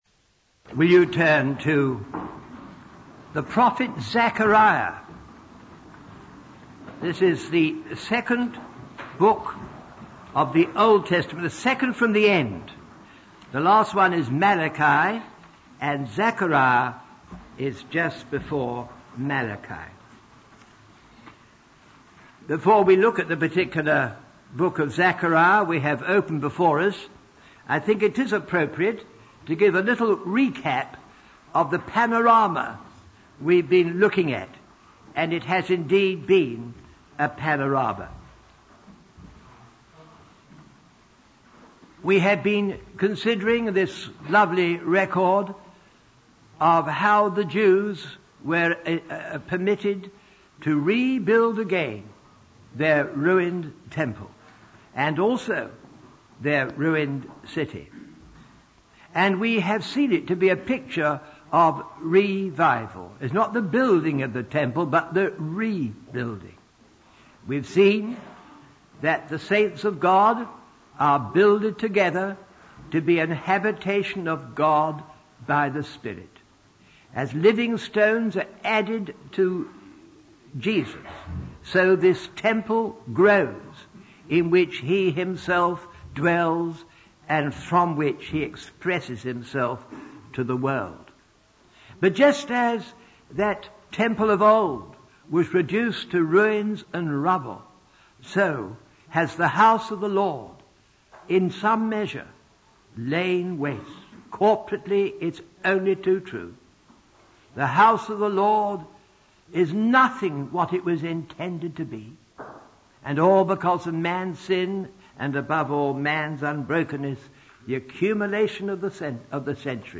In this sermon, the speaker focuses on the story of the prophet Zachariah and the rebuilding of the walls of Jerusalem.